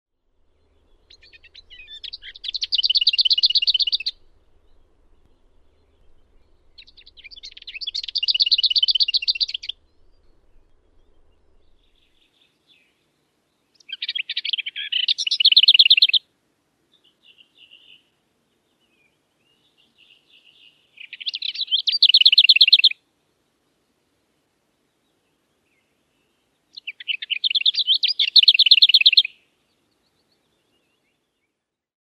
Äänet kuitenkin ovat kantavia, ja laulu korvaan tarttuva.
Hernekertun kutsuääni on aika samanlainen kuin muilla kertuilla, mutta ehkä pikkuisen terävämpi ja iskevämpi ”tsak”.
Ne pysyttelevät laulaessaankin niin taidokkaasti lehvästön tai neulaston suojassa katseilta, että jäisivät helposti huomaamatta, ellei laulunpätkä olisi niin kuuluva ja helposti tunnistettava.
Eräällä verkkosivustolla hernekertun säksättävää säettä kuvaillaan vertauksella ”kuin ravistelisi paperipussia, jossa on kuivia herneitä”.